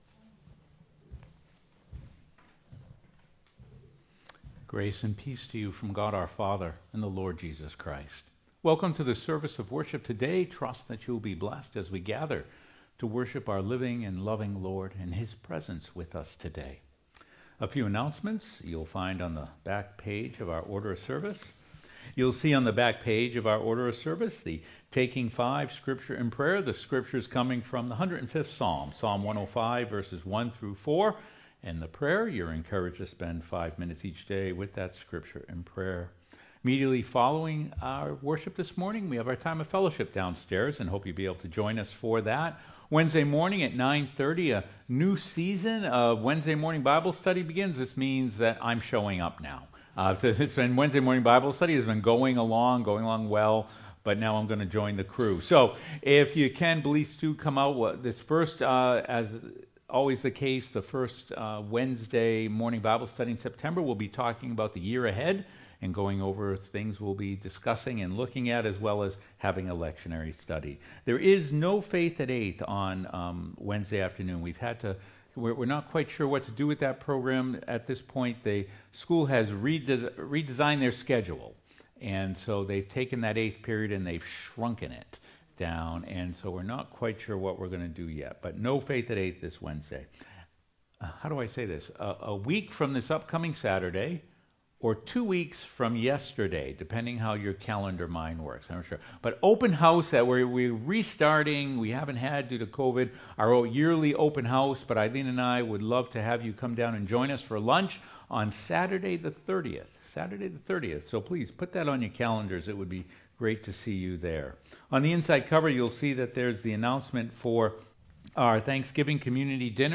sermon-8.mp3